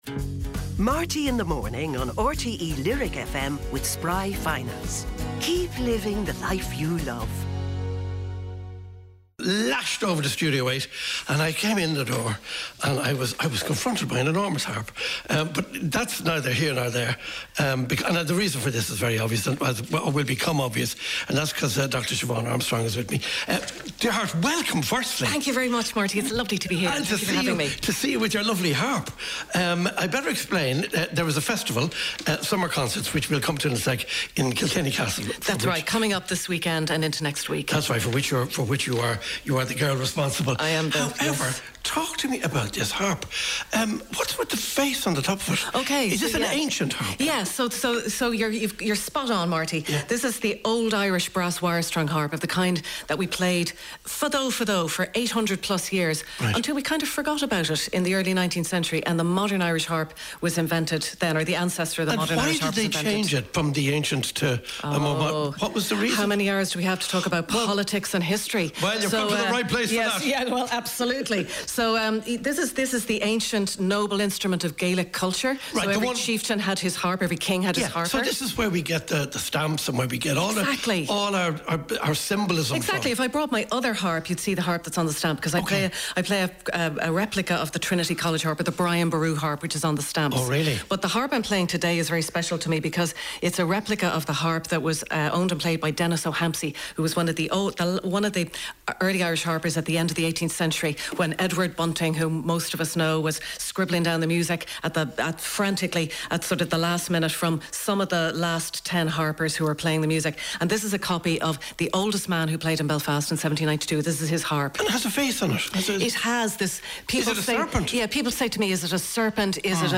This interview is well worth a listen, about 25min.